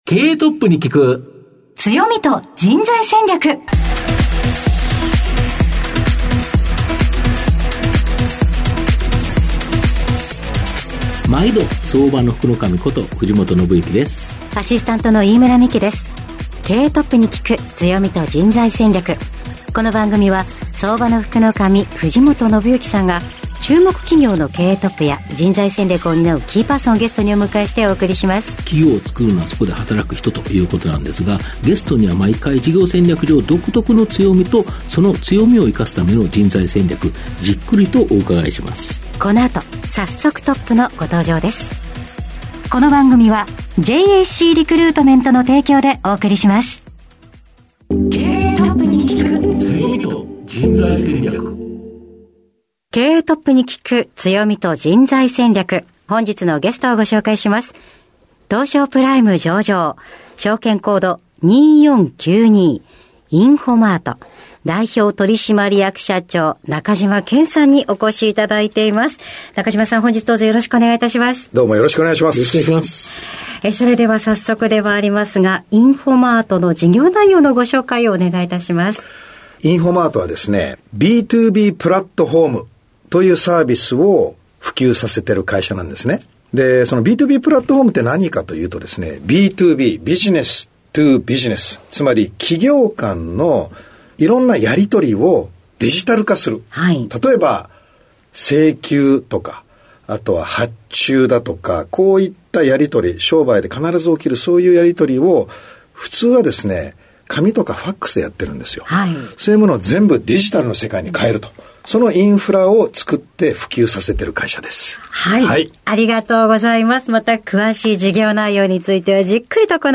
毎回注目企業1社をピックアップし経営トップをゲストにお招きし、事業の側面だけでなく人材戦略の観点からも企業の強みに迫る。トップの人柄が垣間見えるプライベートなQ&Aも。